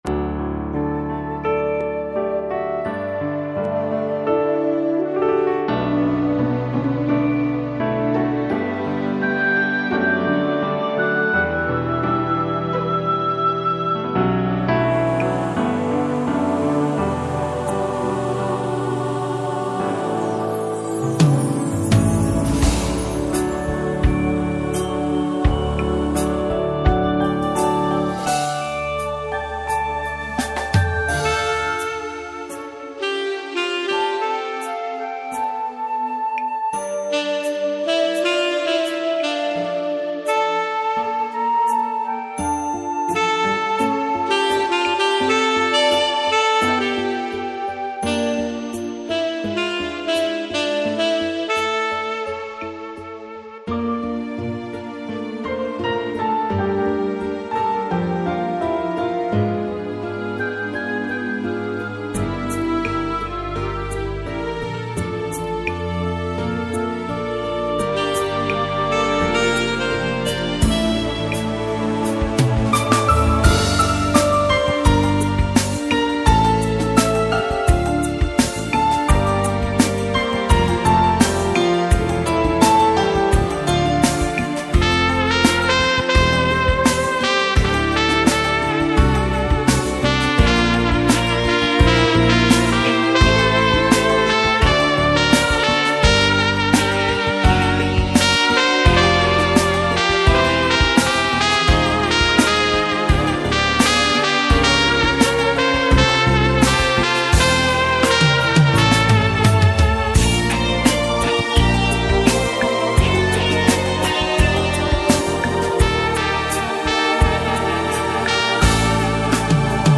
без текста